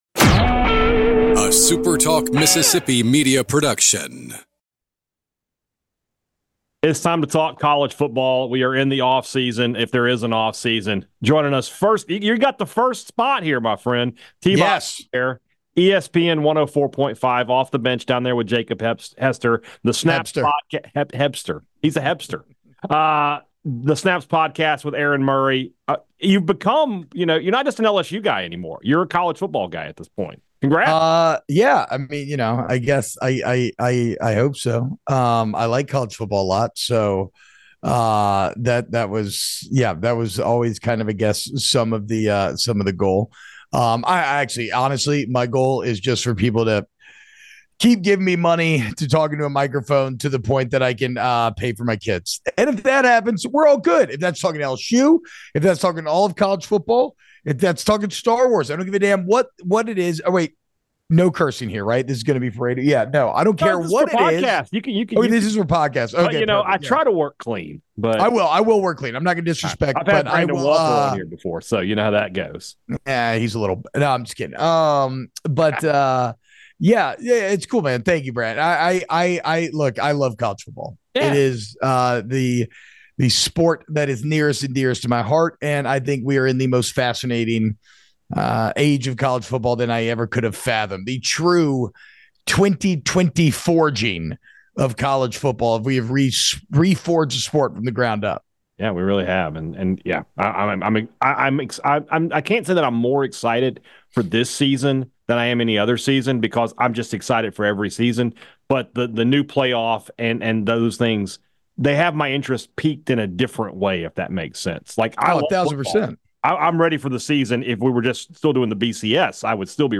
2024 at 1:41 am . 0:00 18:42 Add to My Queue Download MP3 Share episode Share at current time Show notes It's time to turn our attention to college football, so Thunder & Lightning will be bringing you interviews all summer long talking with the biggest names in our favorite sport.